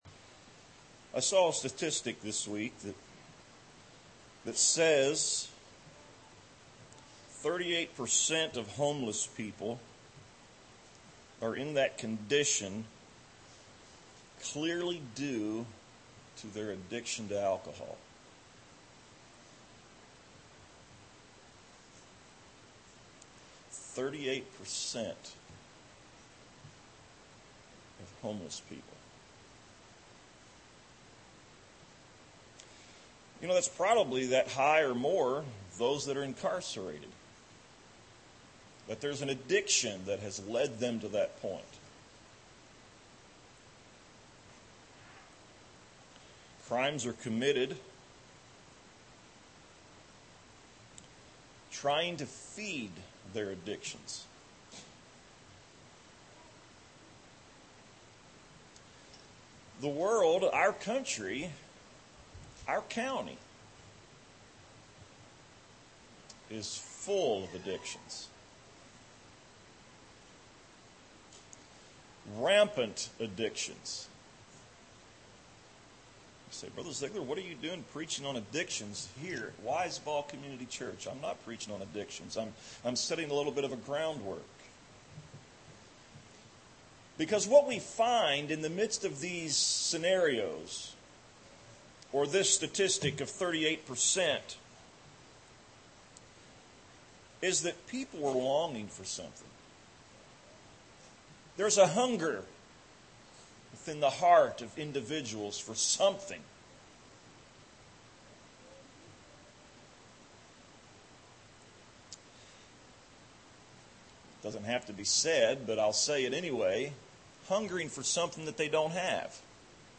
A sermon on “Soul Rest”